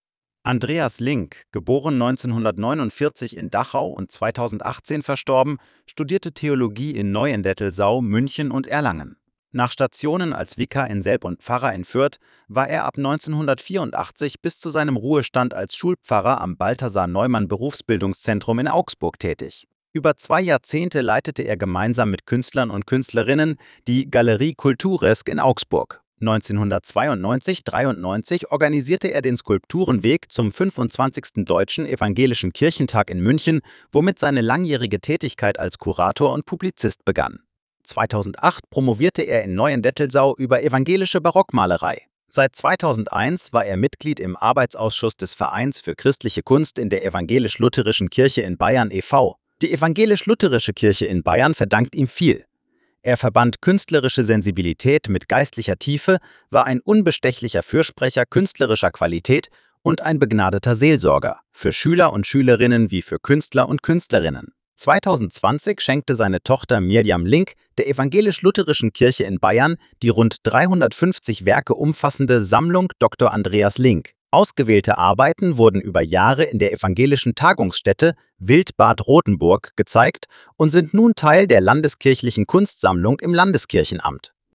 Audiostimme: KI generiert